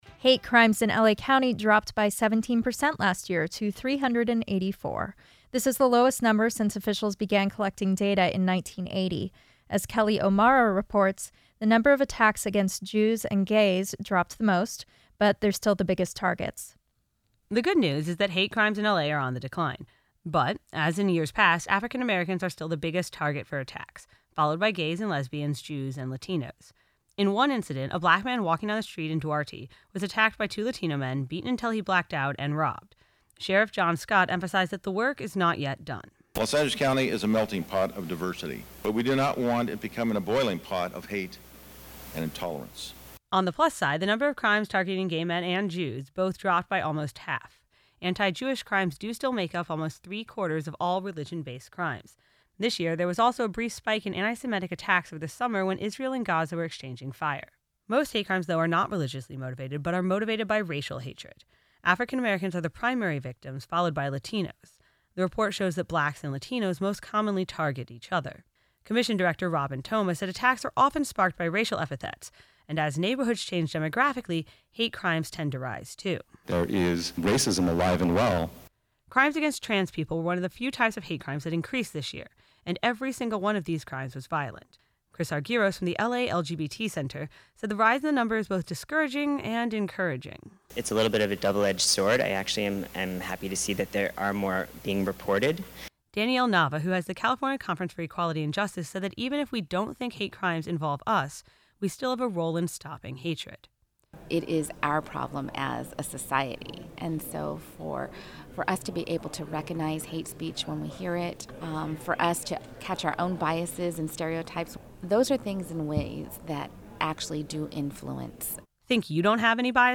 While there have been drastic decreases in certain crimes, this year did see a rise in crimes against trans-people and lesbians. For a full breakdown, listen to our report.